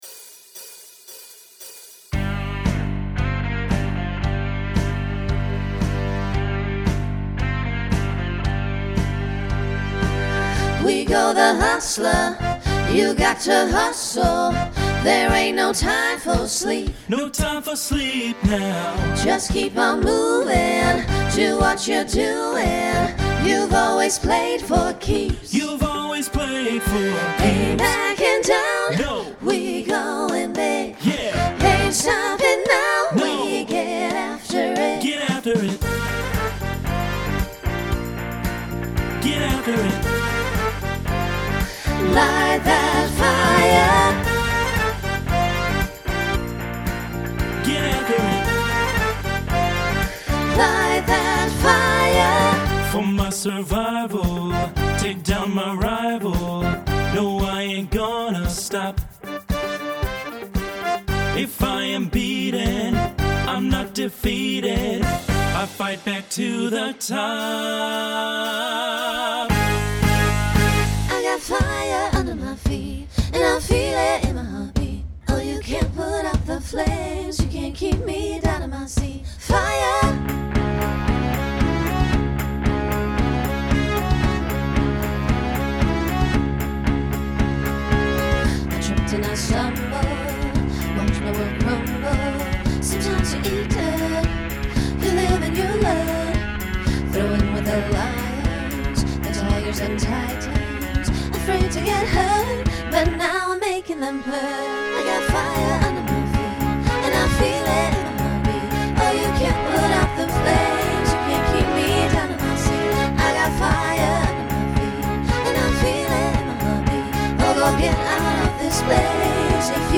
Genre Pop/Dance
Transition Voicing Mixed